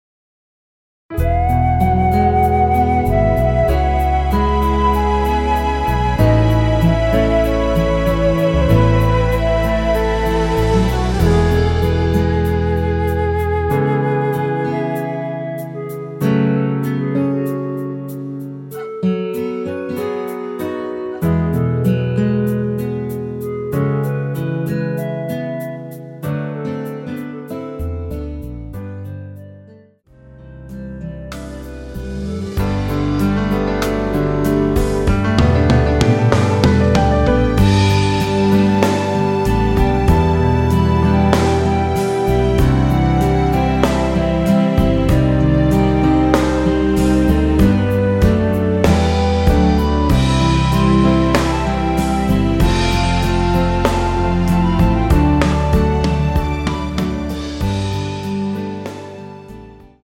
원키에서(-1)내린 멜로디 포함된 MR입니다.
앞부분30초, 뒷부분30초씩 편집해서 올려 드리고 있습니다.
곡명 옆 (-1)은 반음 내림, (+1)은 반음 올림 입니다.
(멜로디 MR)은 가이드 멜로디가 포함된 MR 입니다.